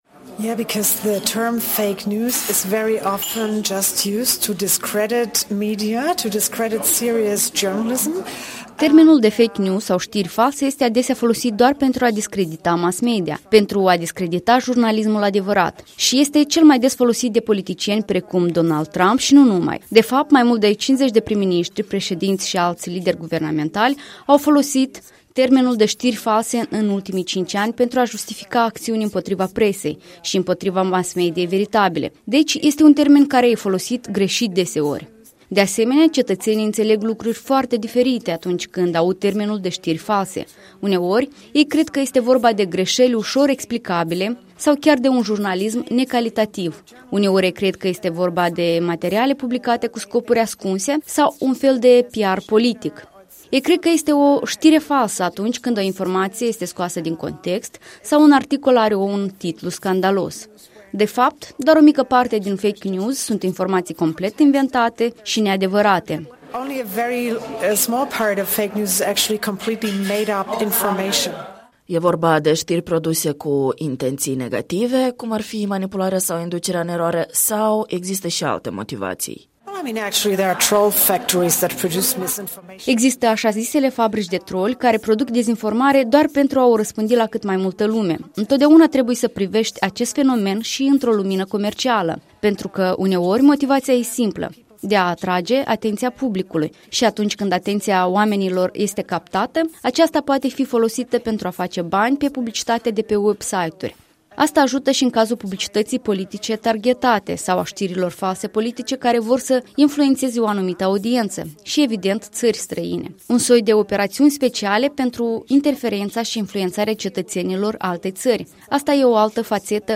Ce ar trebuie să facem, astfel încât în 2020 să evităm greșelile anului 2019? Interviu